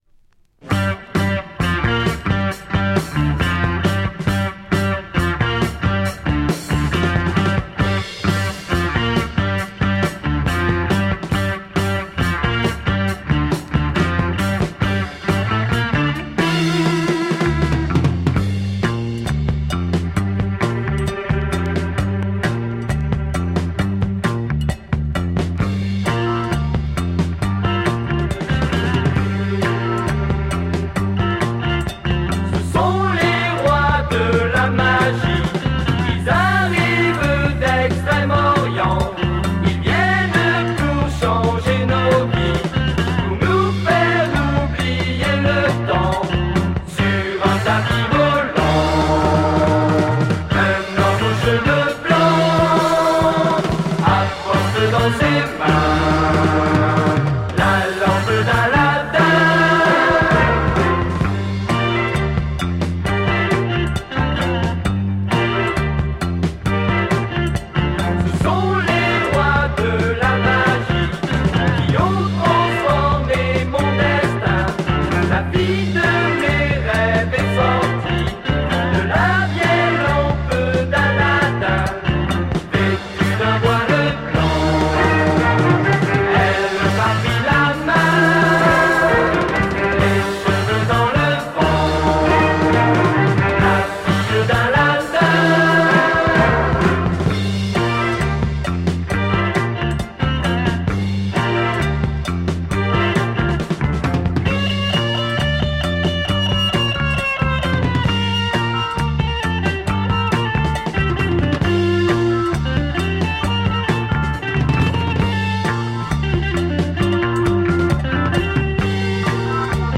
French Progster Psych Lysergic !